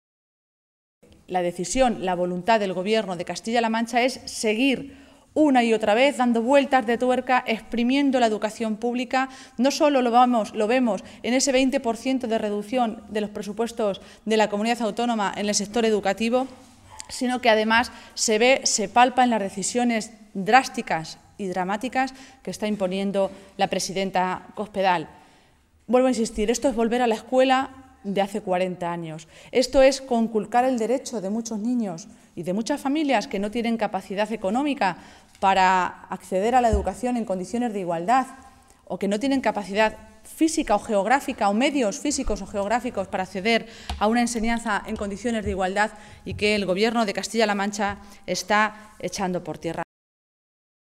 Así se pronunciaba Maestre en una comparecencia ante los medios de comunicación en la que decía que “en las cinco tristes páginas que tiene esa circular se pone por escrito, negro sobre blanco, lo que la señora Cospedal y el consejero luego niegan o matizan en sus declaraciones, y es el desmantelamiento de la educación pública en Castilla-La Mancha”, señalaba.